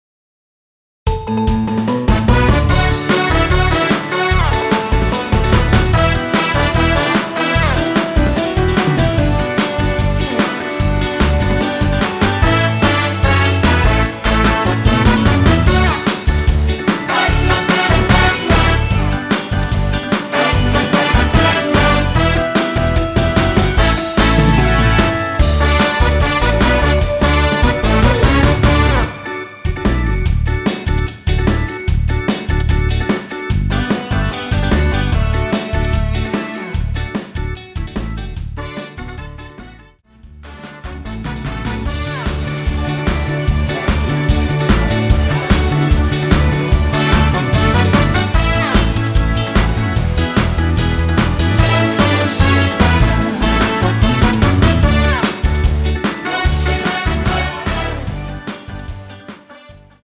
(-2)내린 코러스 포함된 MR 입니다.(미리듣기 참조)
D
◈ 곡명 옆 (-1)은 반음 내림, (+1)은 반음 올림 입니다.